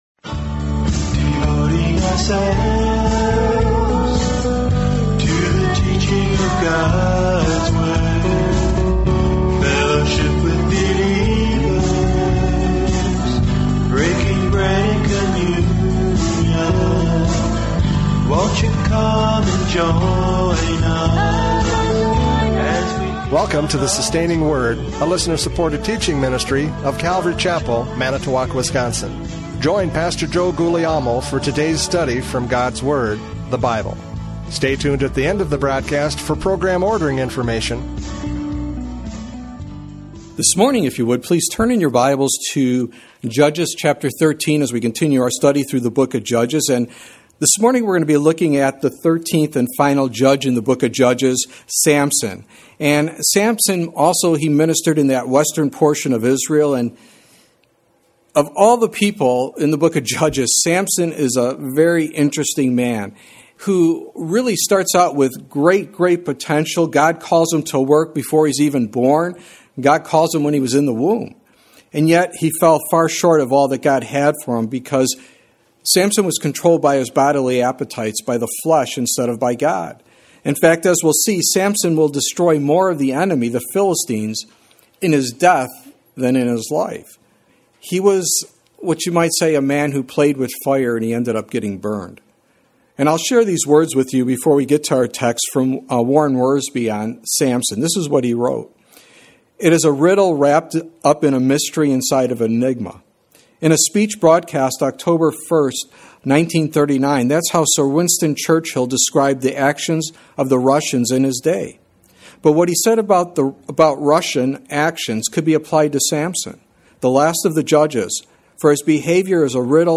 Judges 13 Service Type: Radio Programs « Judges 12 Turmoil Against the Brethren!